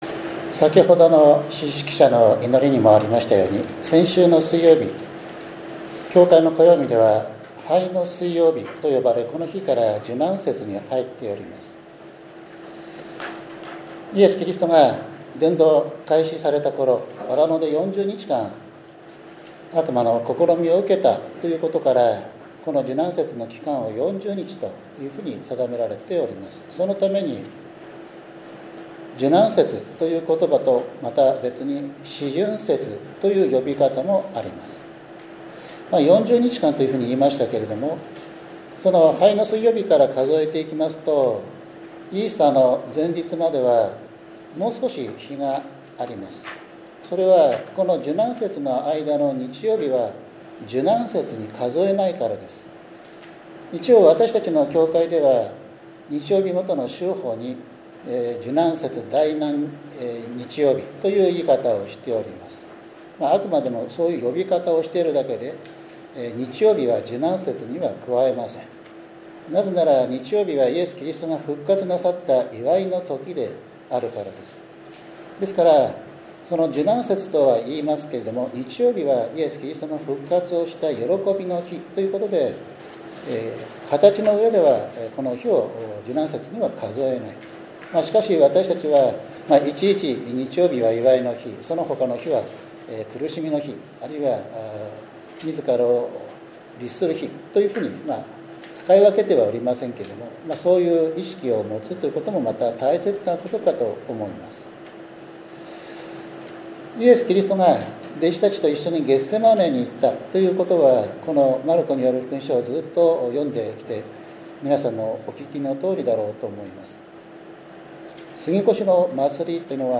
３月９日（日）主日礼拝 ゼカリヤ書１３章７節 マルコによる福音書１４章４３節～５０節